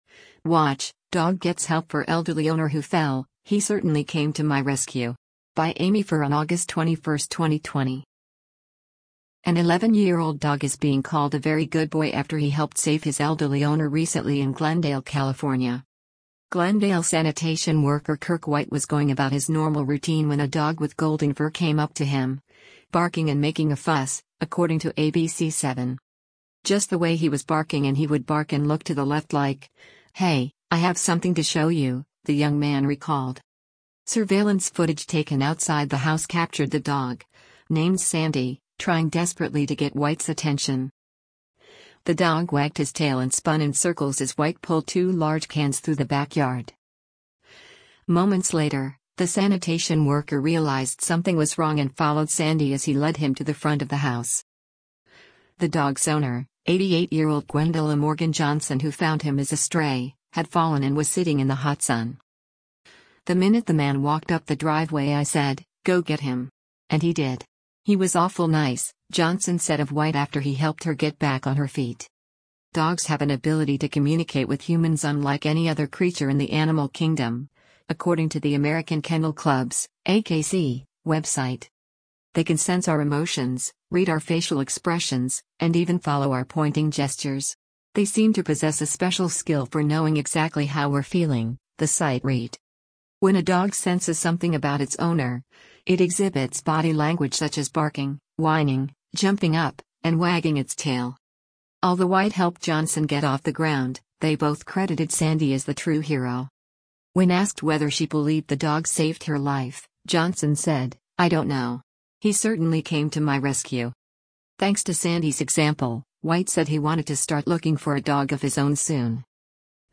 “Just the way he was barking and he would bark and look to the left like, ‘Hey, I have something to show you,’” the young man recalled.